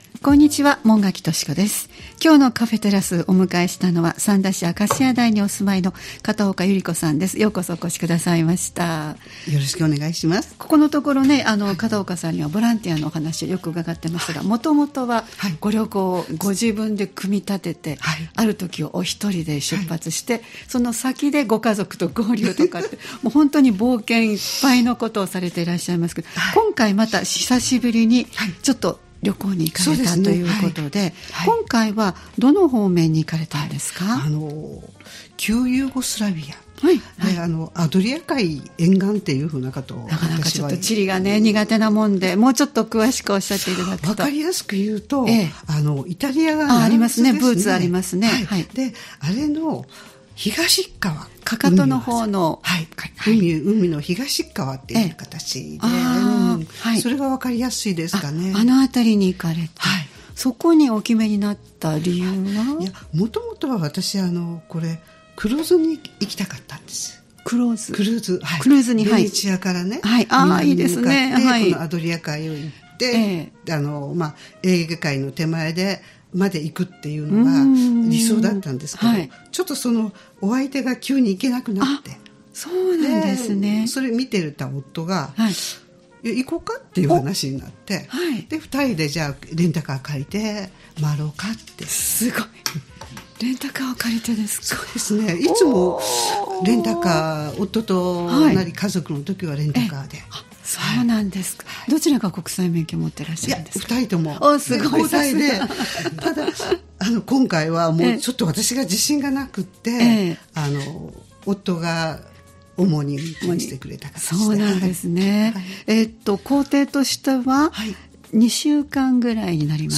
様々なゲストをお迎えするトーク番組「カフェテラス」（再生ボタン▶を押すと放送が始まります）